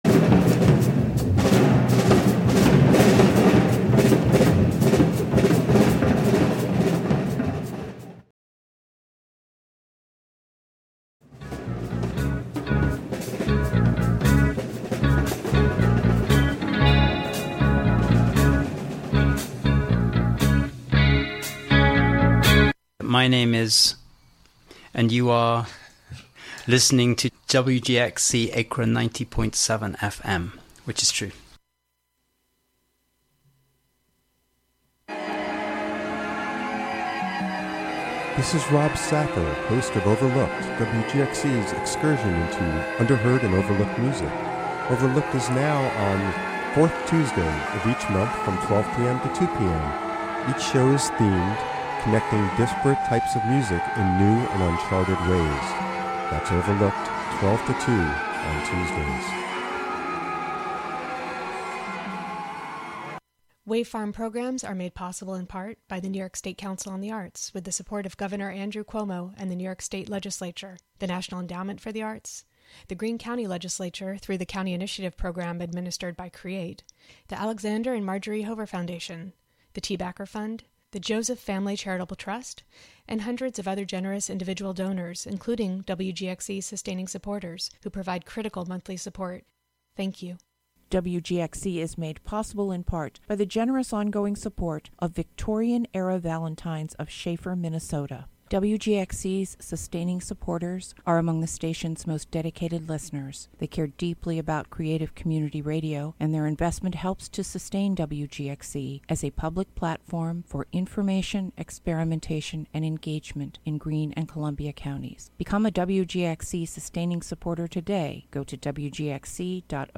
Broadcast from Catskill.